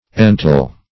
Search Result for " ental" : The Collaborative International Dictionary of English v.0.48: Ental \En"tal\, a. [See Ent- .]